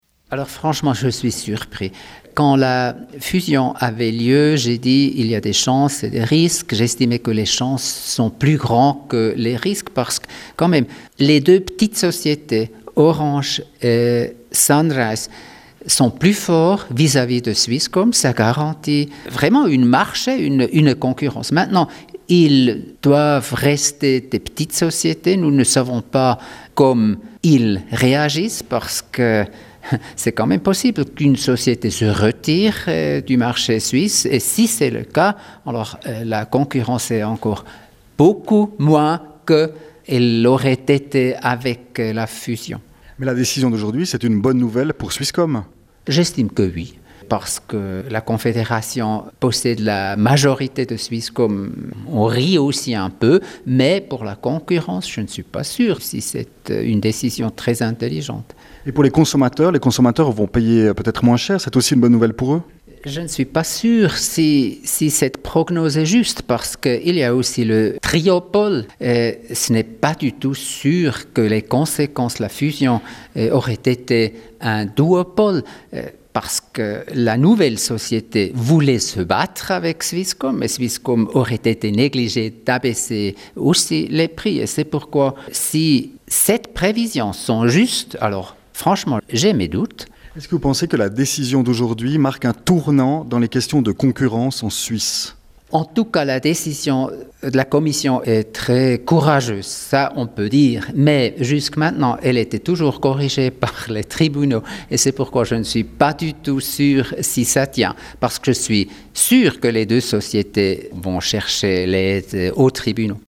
Moritz Leuenberger, ministre de la Communication